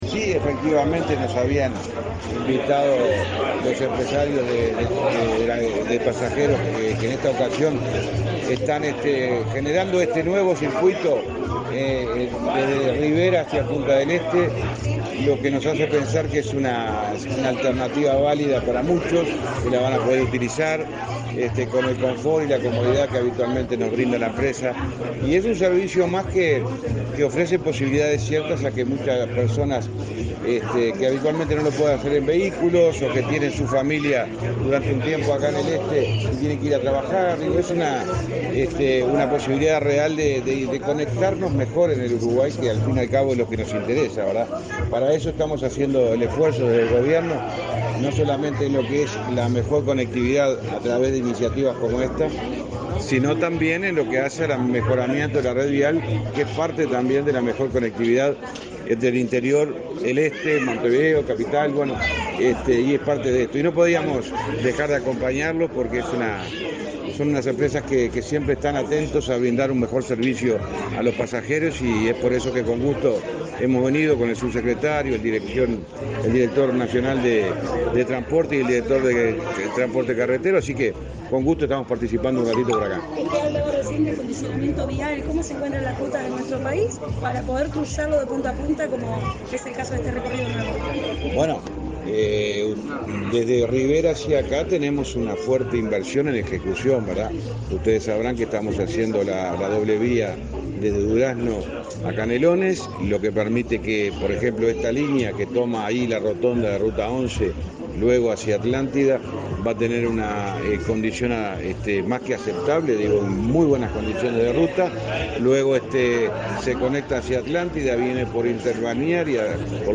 Declaraciones a la prensa del ministro de Transporte, José Luis Falero
Declaraciones a la prensa del ministro de Transporte, José Luis Falero 24/02/2023 Compartir Facebook X Copiar enlace WhatsApp LinkedIn El ministro de Transporte y Obras Públicas, José Luis Falero, participó, este 24 de febrero, en la inauguración de una línea de ómnibus que realizará el trayecto Rivera-Punta del Este.